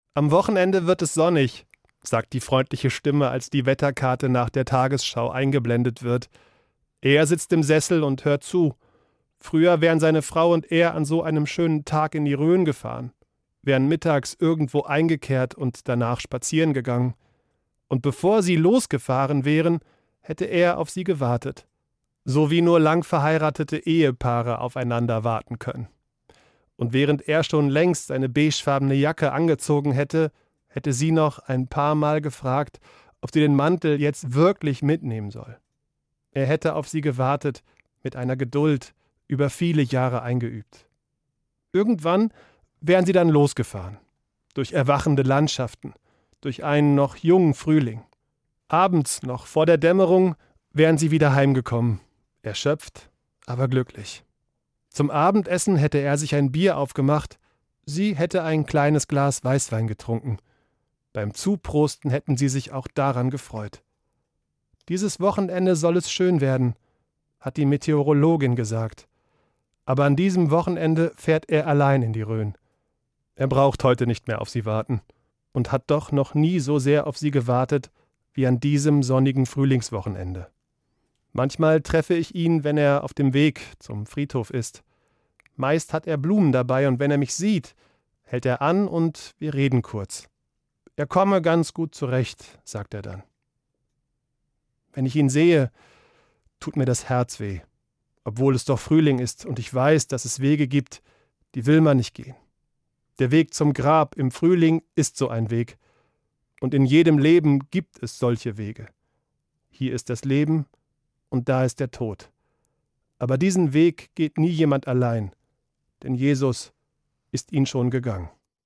Evangelischer Pfarrer, Bad Hersfeld